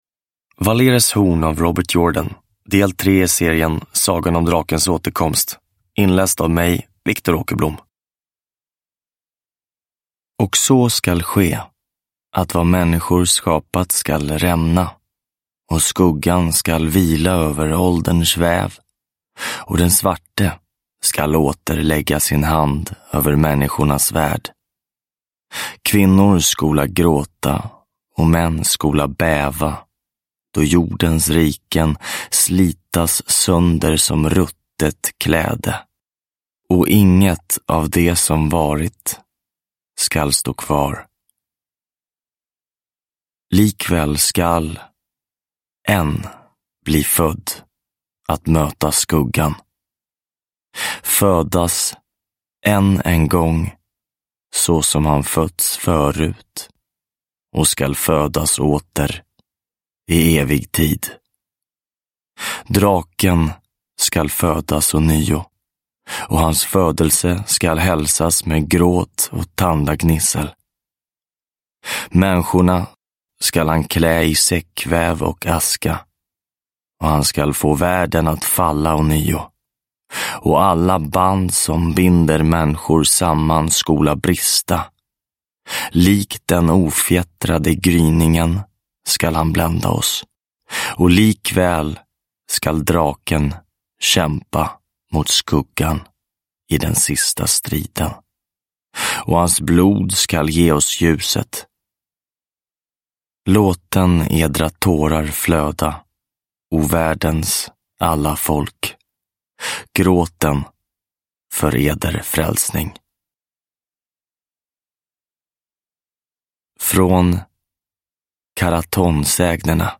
Valeres horn – Ljudbok – Laddas ner